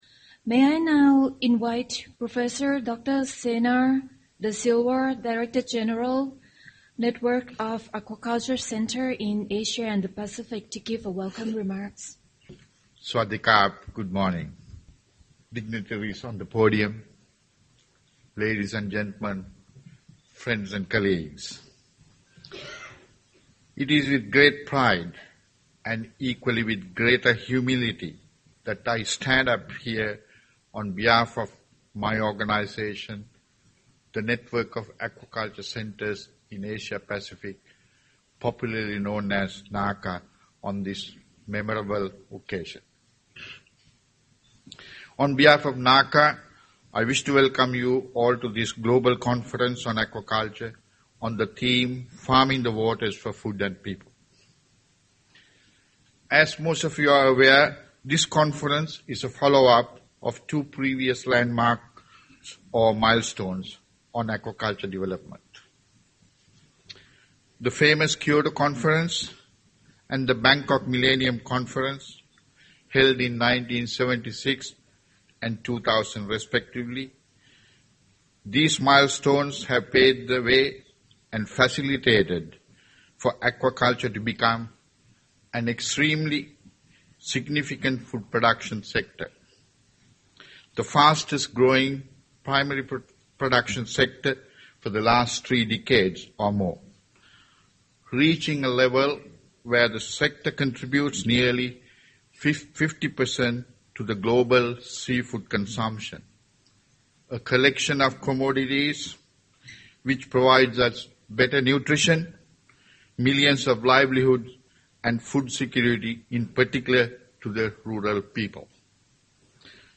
The conference was organised by FAO, the Thai Department of Fisheries and NACA and held in the Mövenpick Resort and Spa, Phuket, Thailand, 22-25 September.